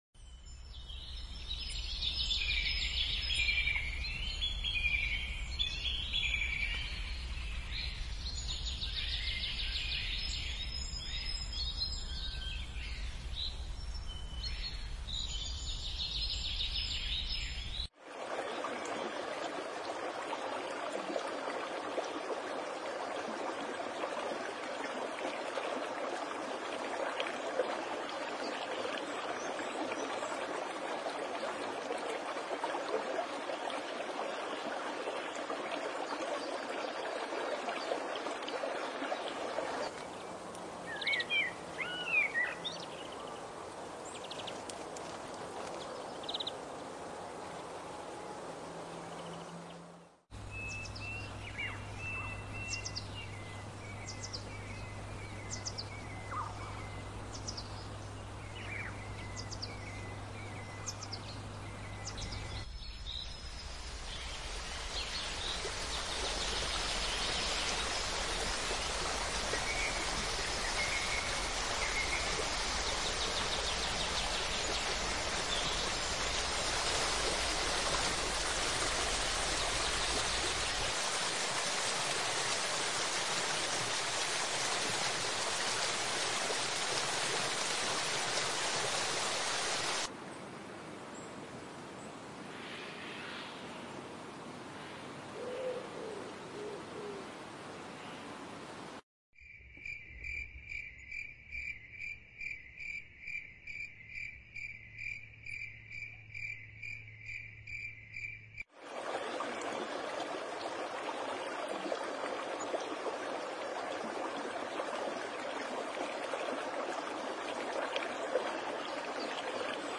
Forest-In-4K-The-Healing-Power-Of-Nature-Sounds-Forest-Sounds-Scenic-Relaxation-Film.mp3-1.mp3